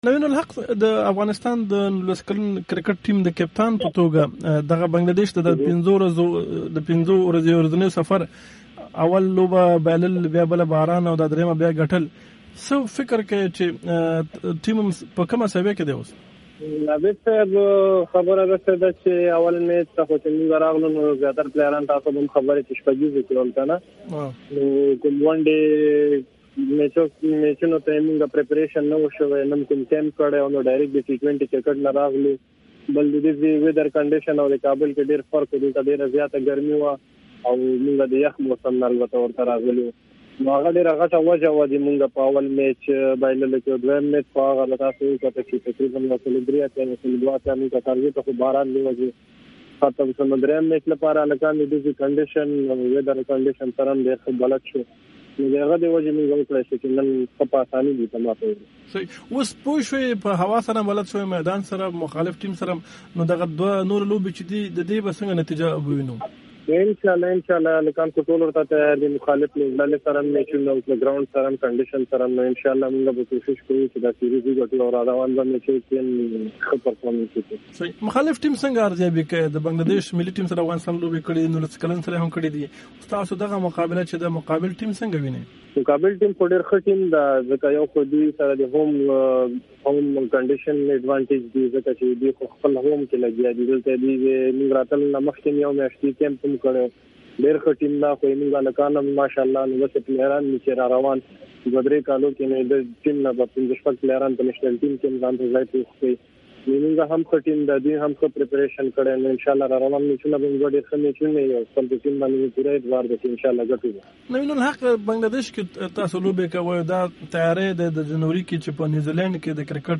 د افغانستان د کرکټ د نولس کلنو لوبغاړو ټیم کپتان نوین الحق سره مرکه